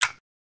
Bail Close.ogg